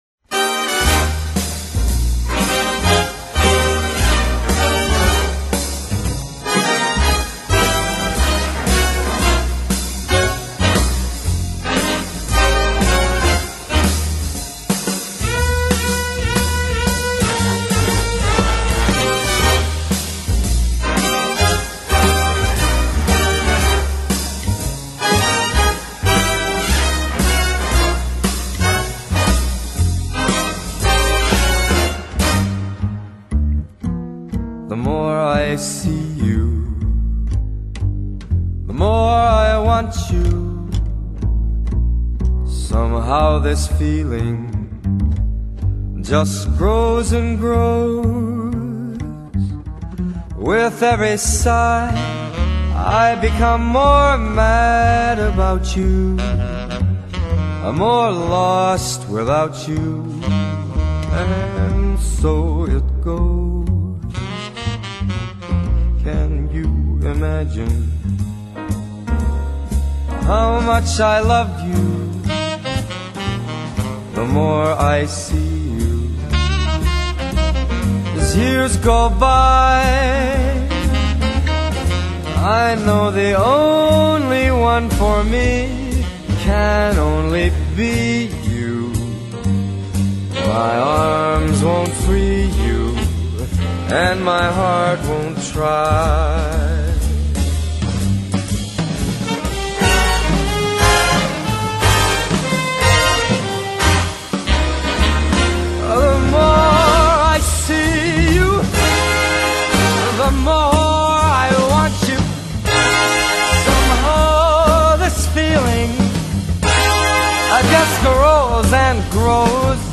音乐类型：爵士乐
把摇滚乐的帅气转变成爵士乐的玩性